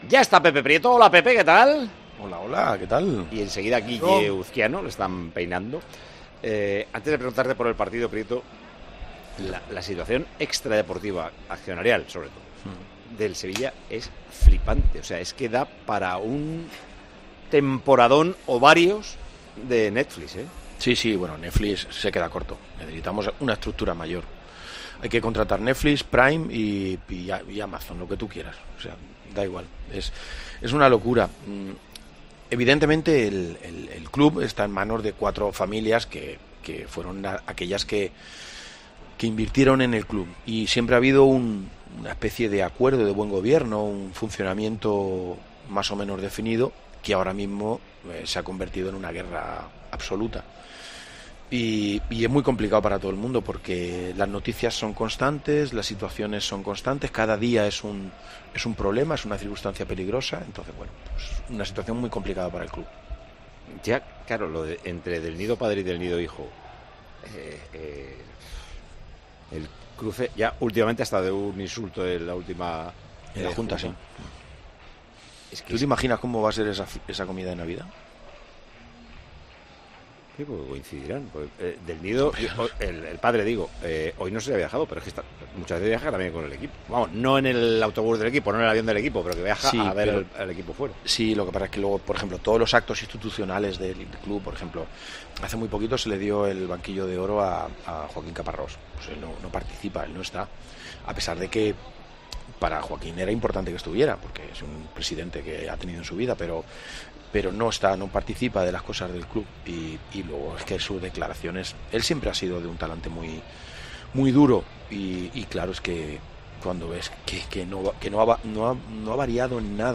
El exjugador del Sevilla y el presentador de Tiempo de Juego trataron en el programa la compleja crisis accionarial por la que está atravesando el club hispalense.